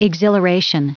Prononciation du mot exhilaration en anglais (fichier audio)
Prononciation du mot : exhilaration